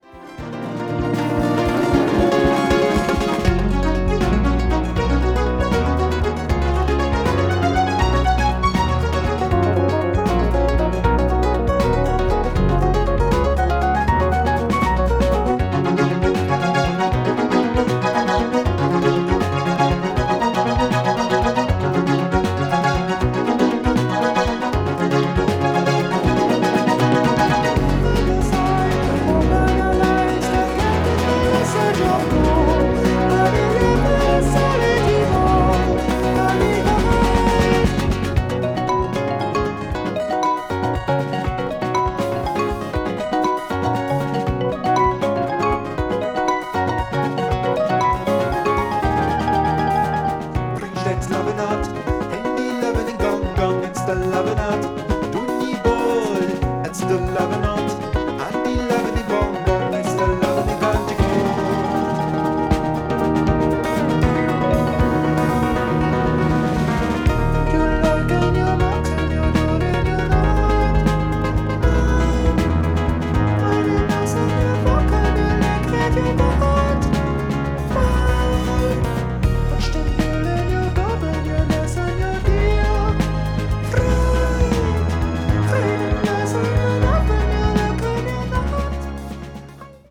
シンフォニックな展開も素晴らしいです。